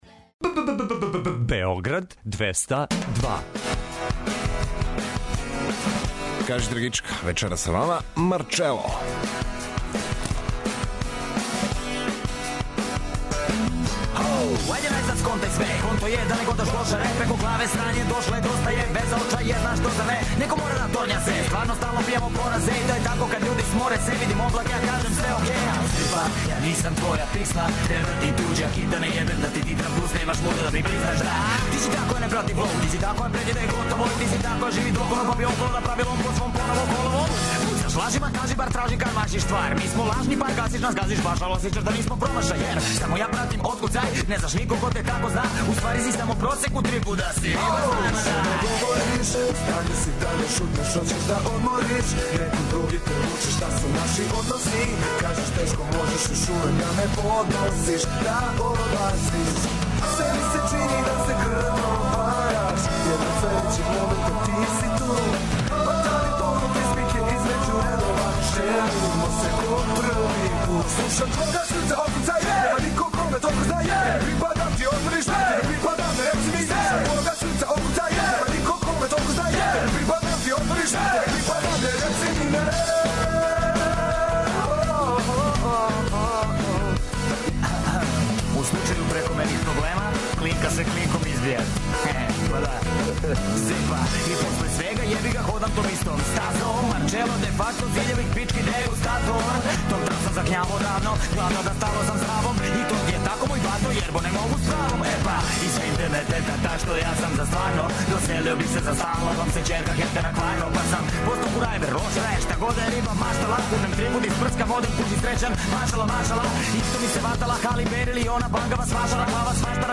Вечерас, у новом издању емисије ''Кажи драгичка'', ваш гост-домаћин биће репер и писац Марко Шелић Марчело!